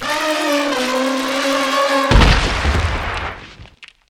ogg / general / combat / enemy / caultdie1.ogg